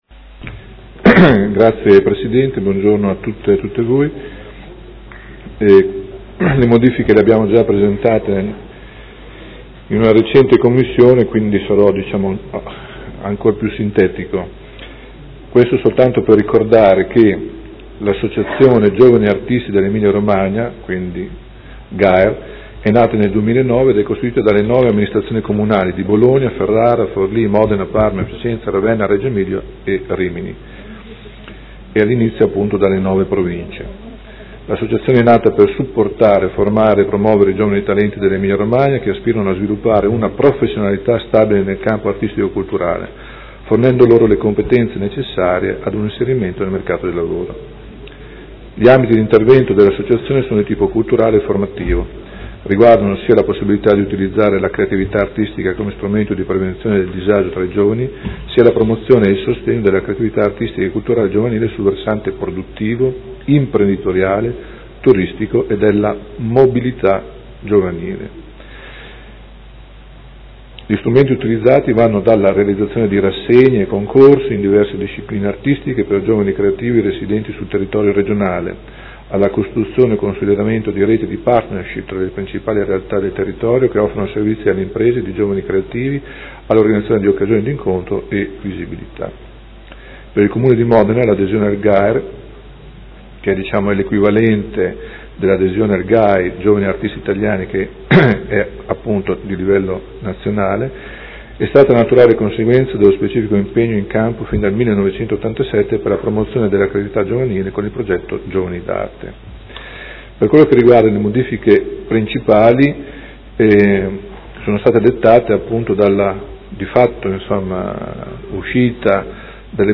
Seduta del 9/06/2016 Delibera. Associazione Giovani Artisti dell’Emilia Romagna (GA/ER) – Ratifica modifiche Statuto
Audio Consiglio Comunale